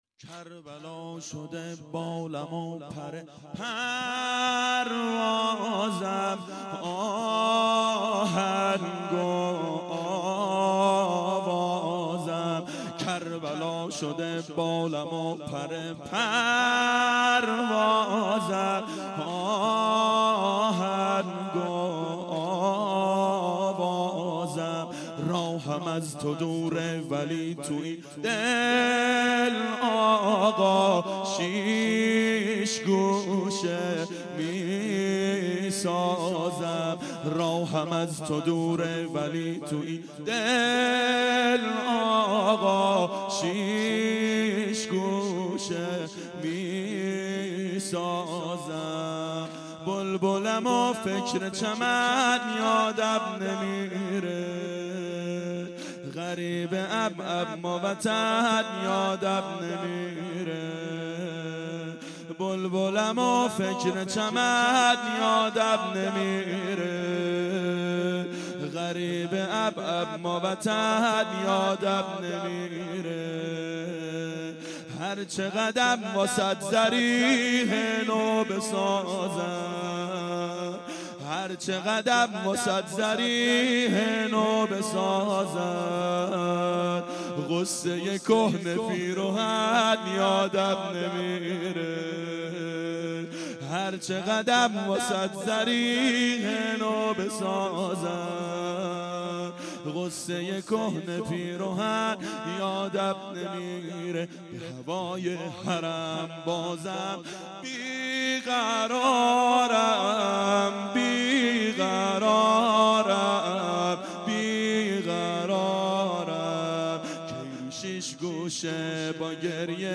دهه اول صفر سال 1392 هیئت شیفتگان حضرت رقیه سلام الله علیها